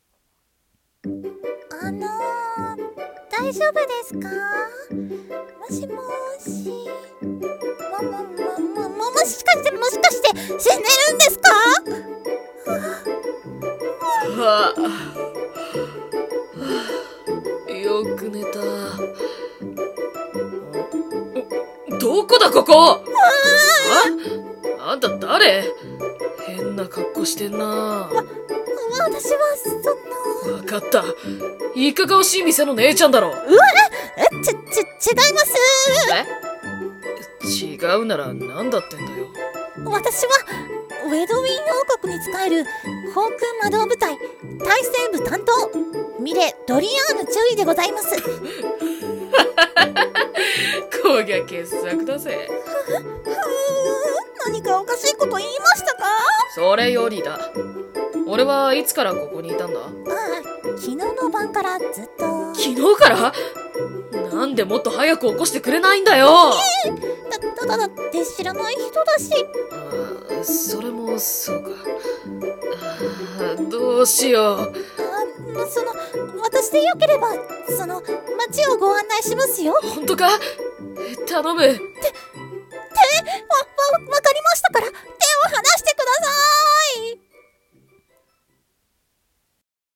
BGM:ポコポコしたBGM【オリジナル】